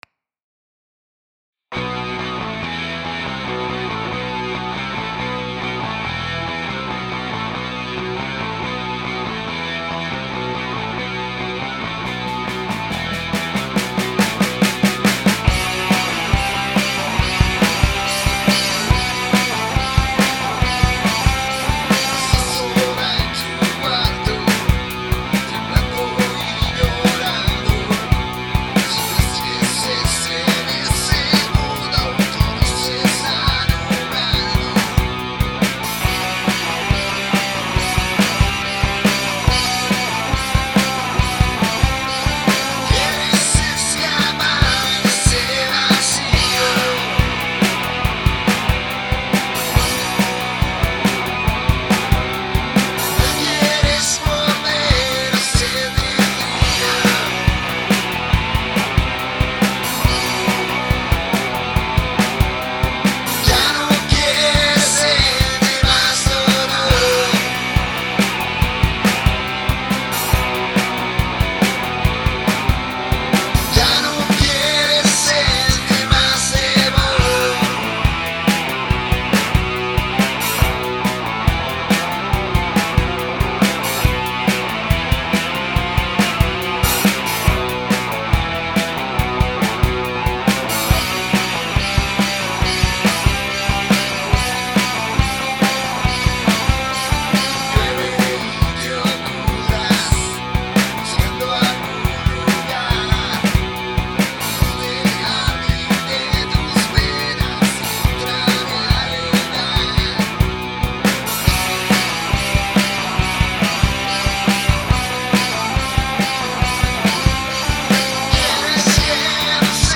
Compongo, canto y toco la guitarra.
Rock Alternativo